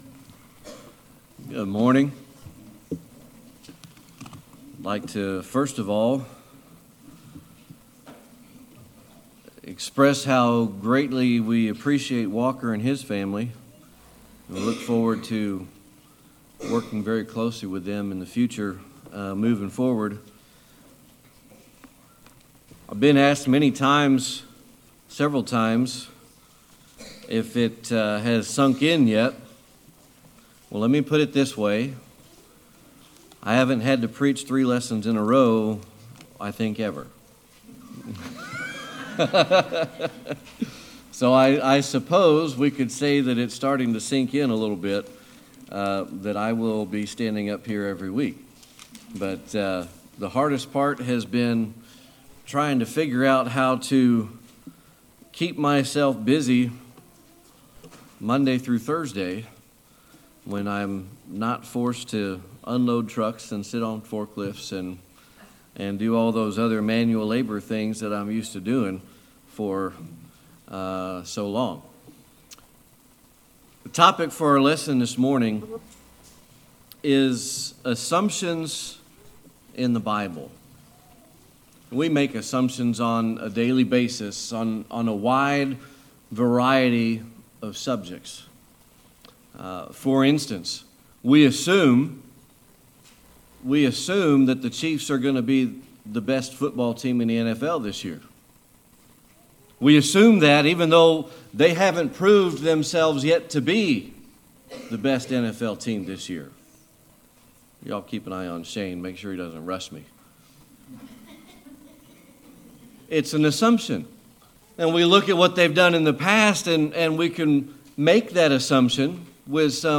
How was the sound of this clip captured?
2 Kings 5:1-14 Service Type: Sunday Morning Worship The topic for our lesson this morning is assumptions in the Bible .